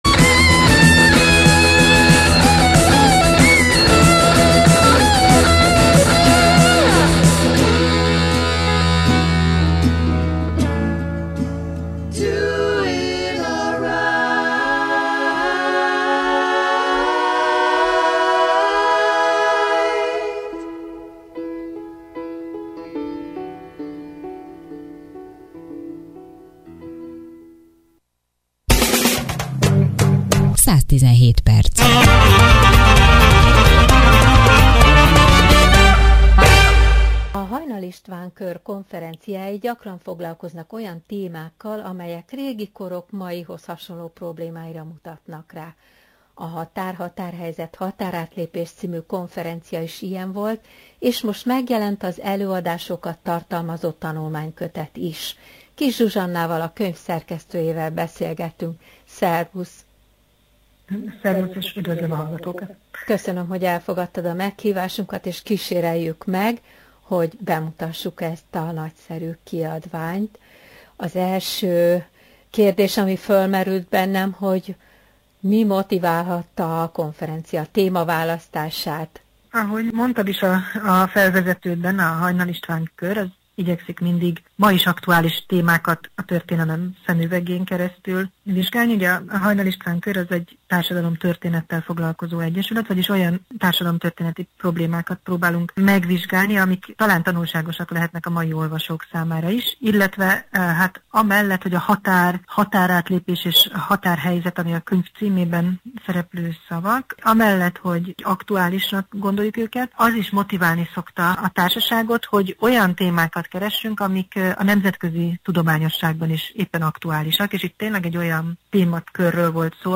(A beszélgetés a hanganyag legelején található!)